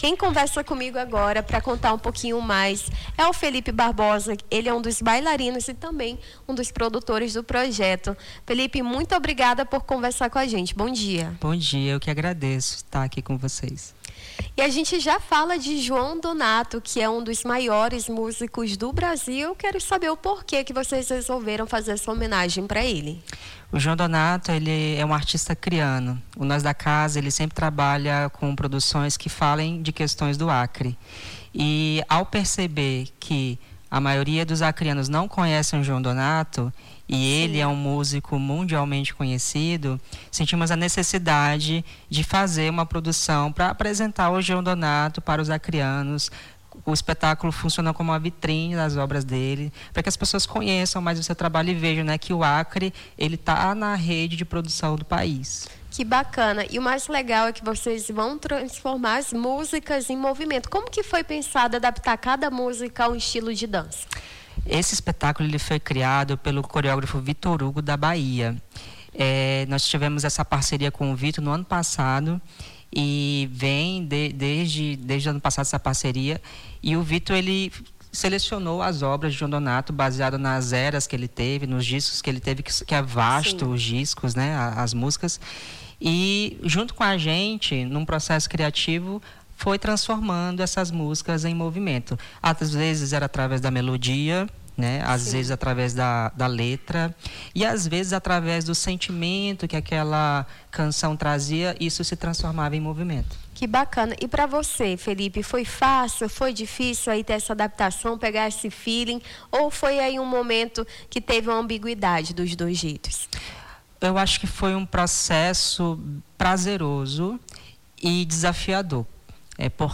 Nome do Artista - CENSURA - ENTREVISTA (ESPETACULO TUDO TEM DONATO) 24-10-25.mp3